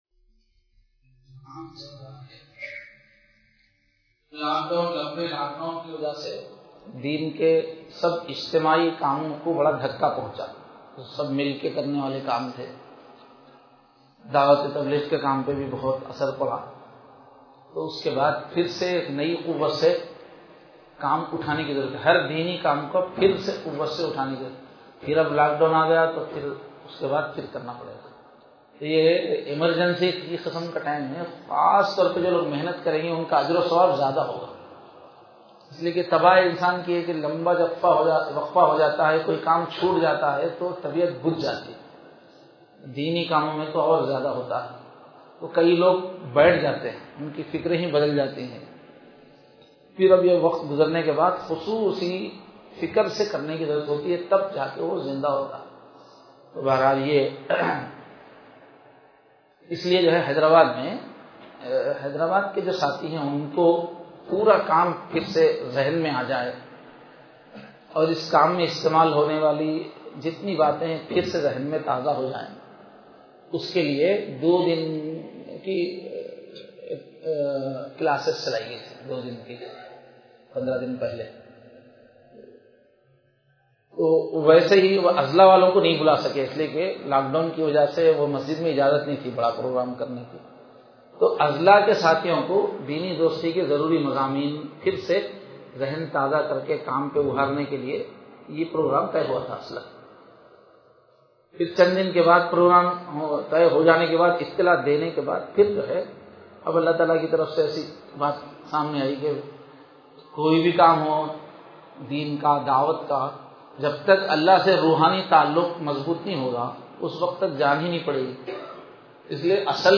Bayanath